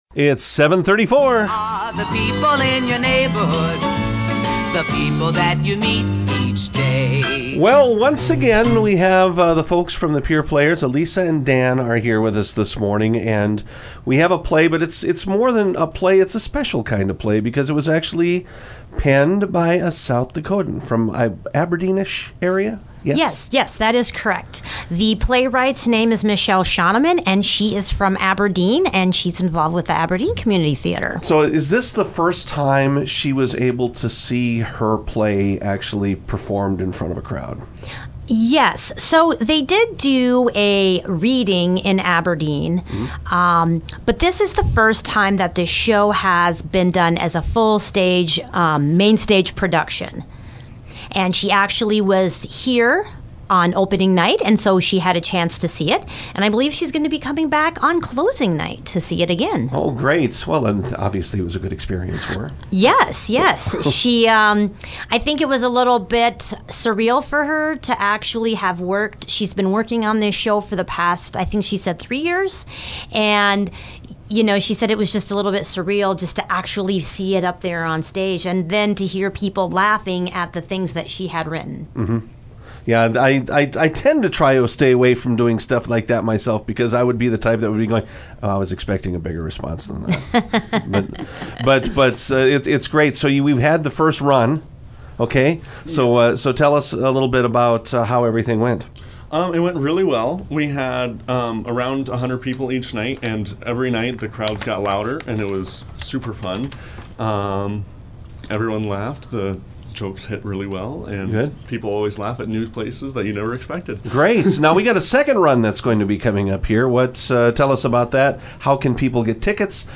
Today’s People In Your Neighborhood includes conversations about the final performances of Pierre Players production of “Inconsequential Dreams” and the monthly Boys and Girls Club of the Capital Area Mega Raffle drawing.